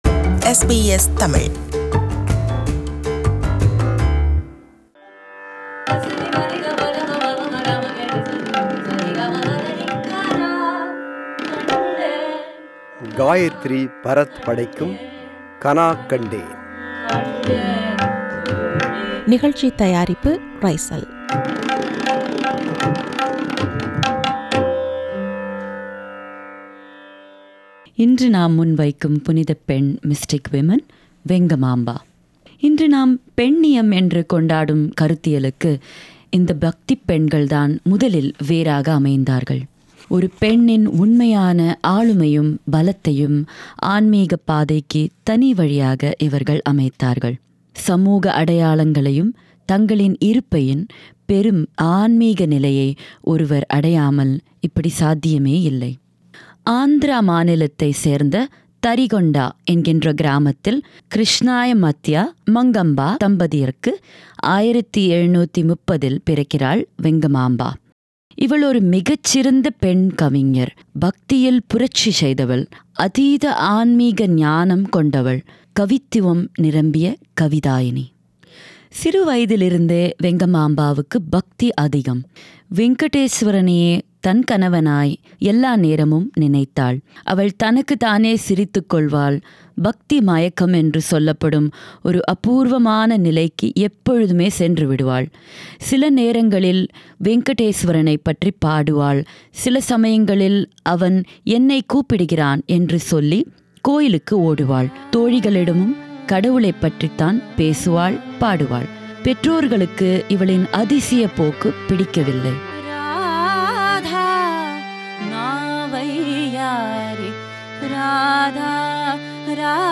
தெய்வீகத்தை, மறைபொருளைத் தேடிய ஒன்பது பெண்களின் வாழ்க்கையையும், அவர்களின் பாடல்களையும் (mystic women) பாடி, விவரிக்கும் தொடர் இது.
Harmonium
Tabla
Mridangam
Tanpura
Studio: SBS